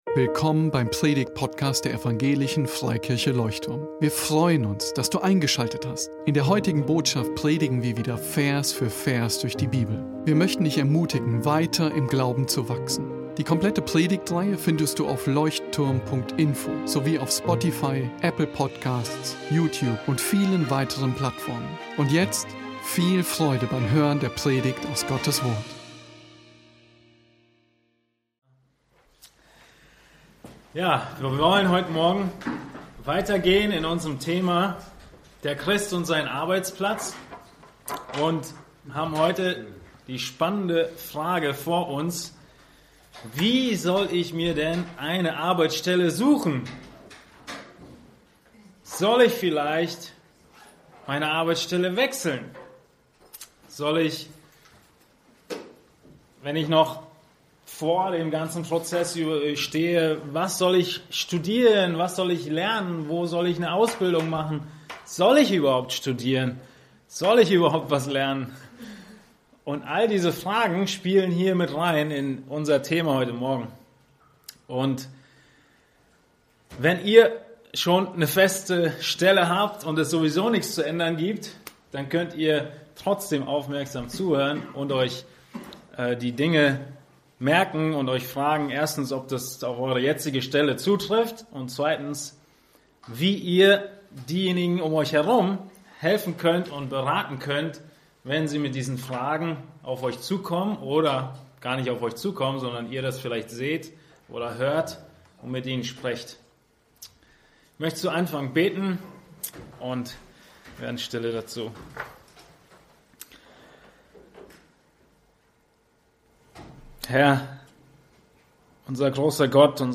Wie wähle ich eine Arbeitsstelle? ~ Leuchtturm Predigtpodcast